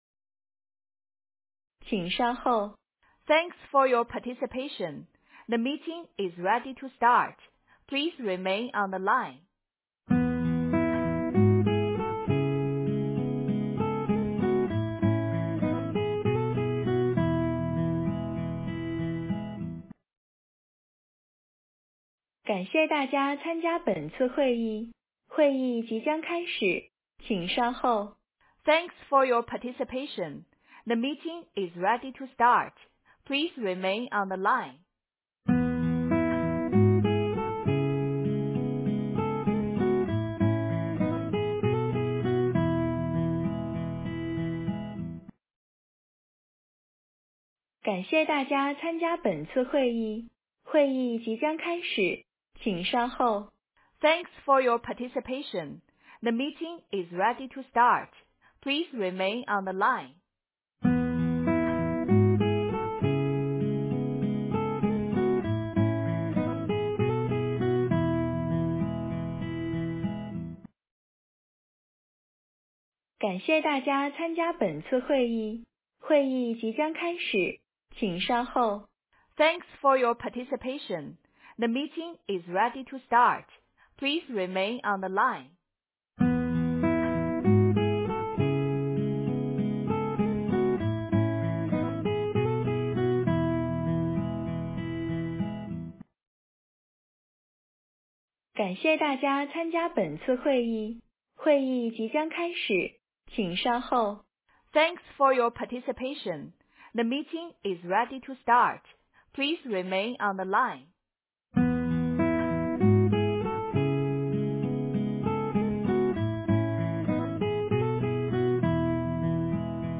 【Audio】Zhou Hei Ya International Holdings Company Limited-2024 Annual Results Conference